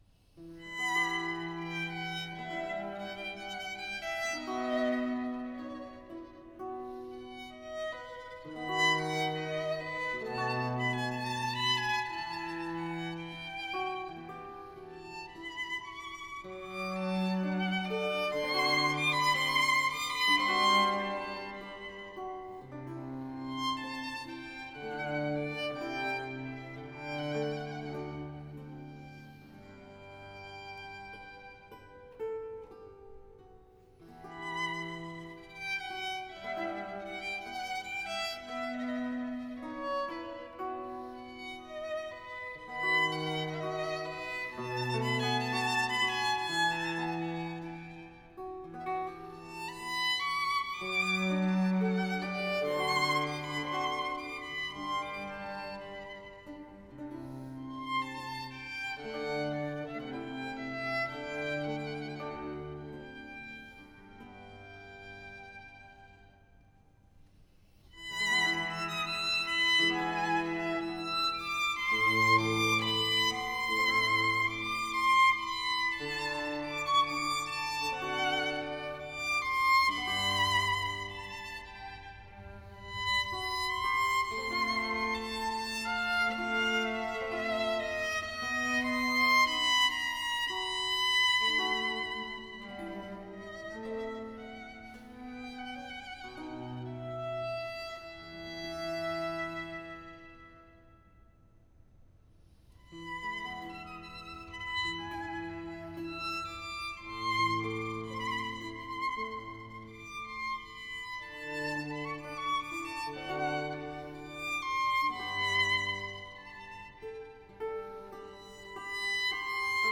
05 Largo Violin Concerto in a RV 335 _quot_Il Cucu_quot_